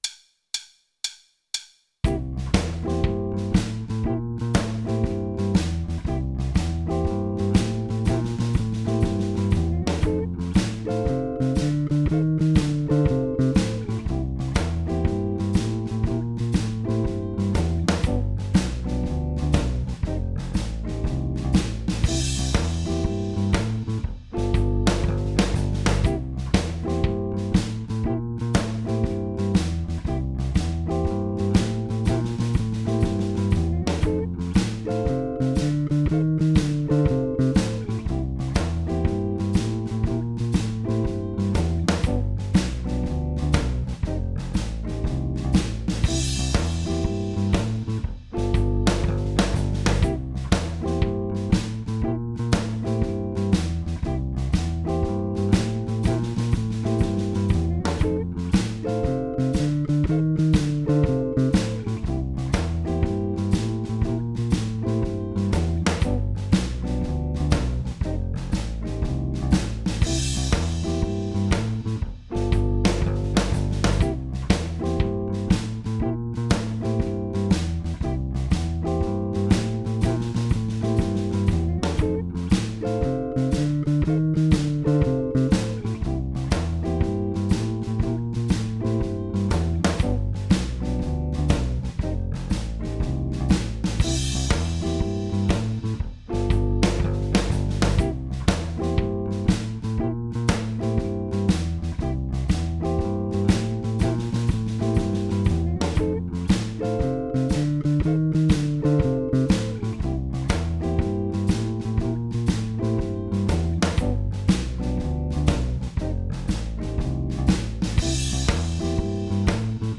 zagrywka bluesowa
blues-c.mp3